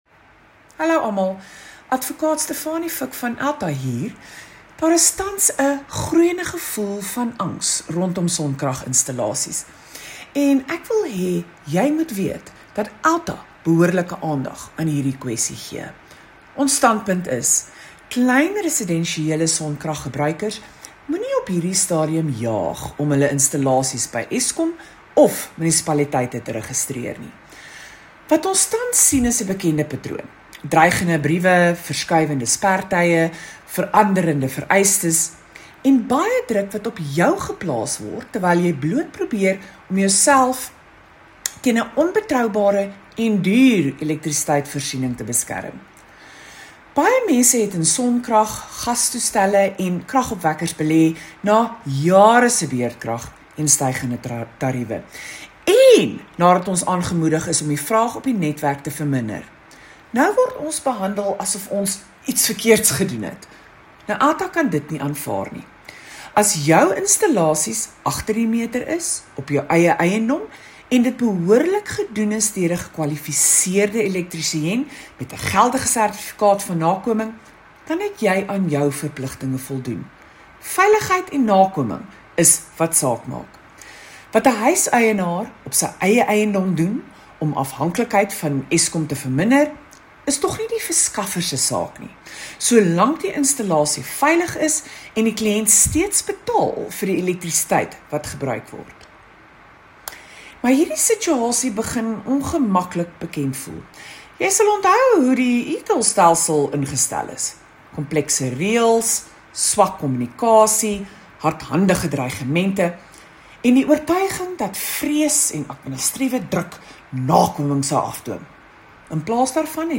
A soundclip with comment in Afrikaans